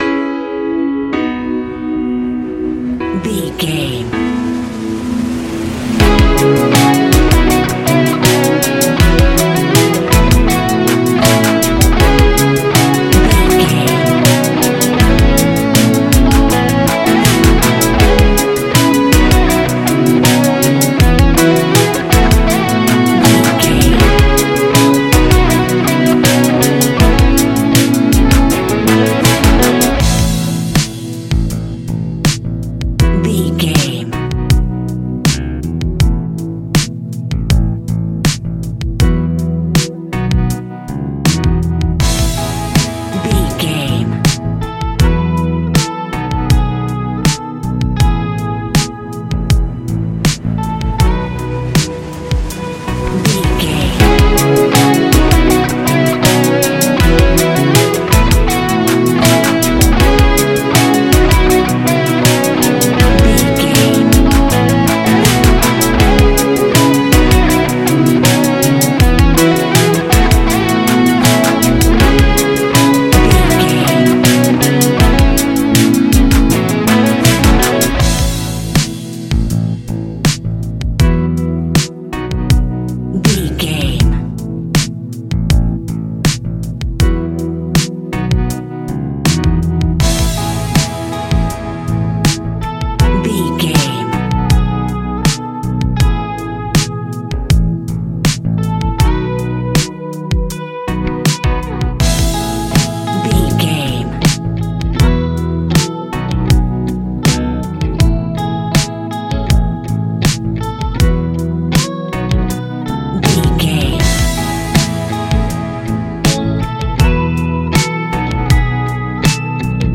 Ionian/Major
ambient
electronic
new age
downtempo
synth
pads
drone
instrumentals